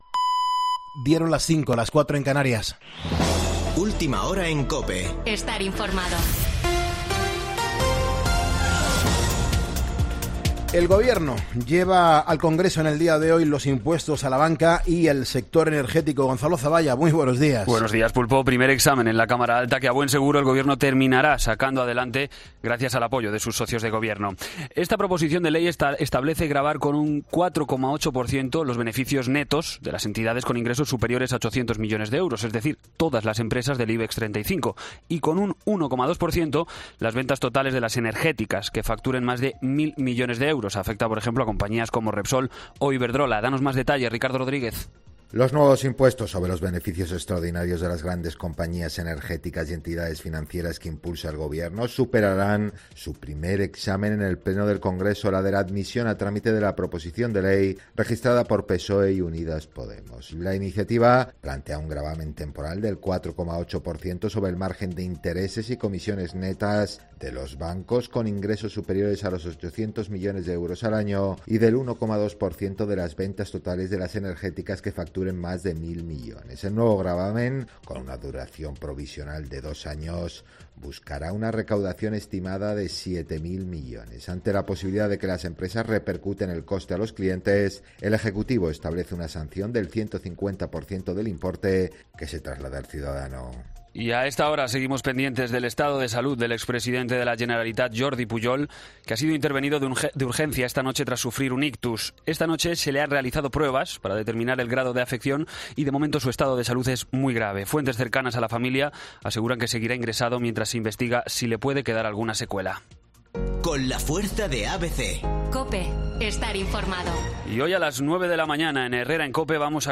Boletín de noticias COPE del 13 de septiembre a las 05:00 horas